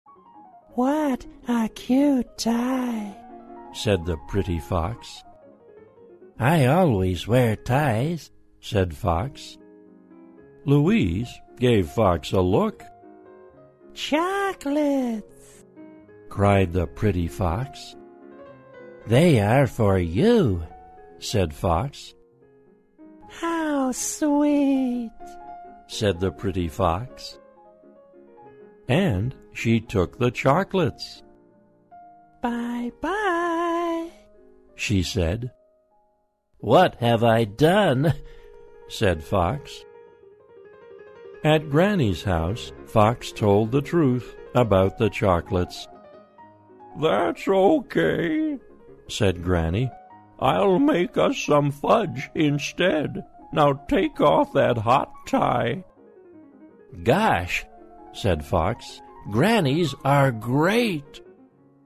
在线英语听力室小狐外传 第26期:可爱的领带的听力文件下载,《小狐外传》是双语有声读物下面的子栏目，非常适合英语学习爱好者进行细心品读。故事内容讲述了一个小男生在学校、家庭里的各种角色转换以及生活中的趣事。